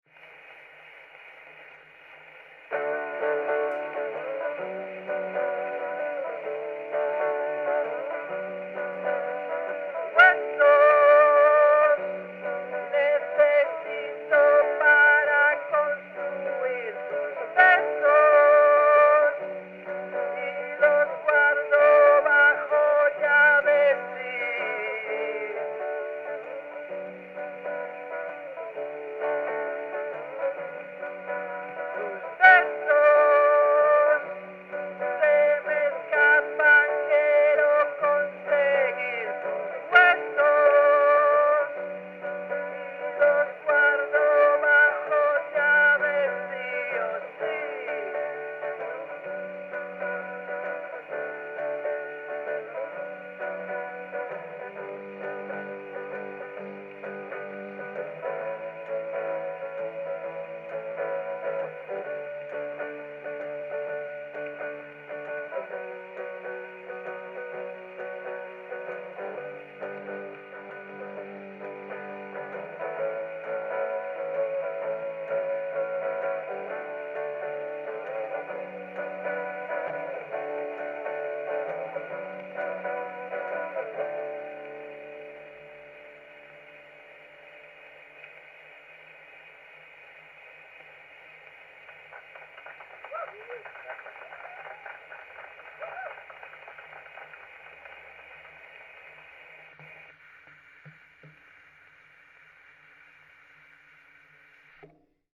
Original sound from the phonographic cylinder.